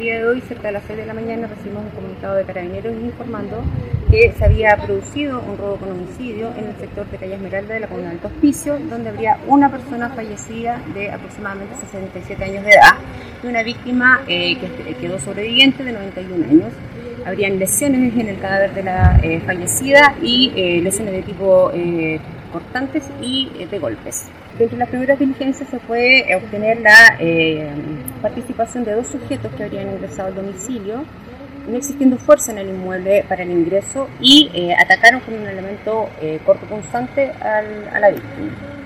CUNA-FISCAL-ECOH.mp3